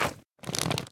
mob / magmacube / jump1.ogg
should be correct audio levels.
jump1.ogg